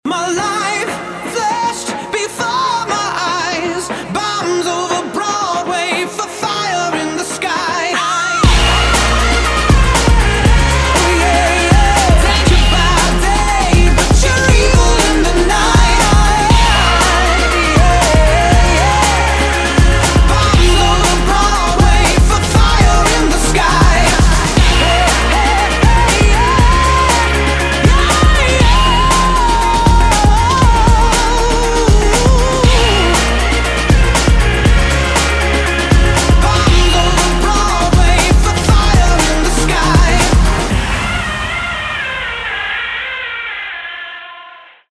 • Качество: 320, Stereo
поп
заводные